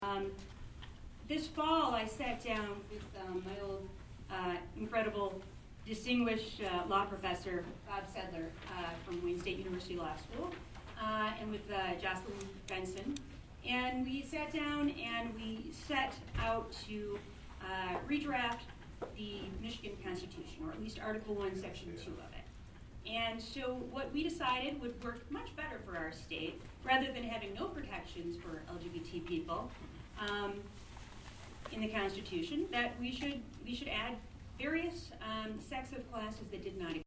ERRATA: Correcting a misstatement in my reporting from Monday night’s LGBTQ forum in Ann Arbor
That statement came from civil rights attorney Dana Nessel’s opening remarks: